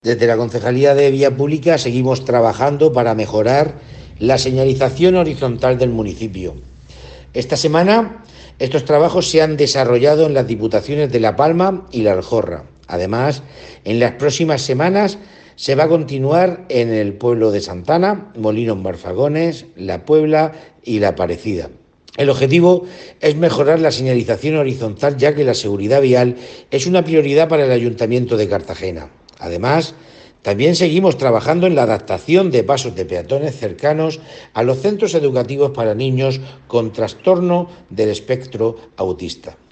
Enlace a Declaraciones de Juan Pedro Torralba sobre mejoras en la señalización horizontal en el municipio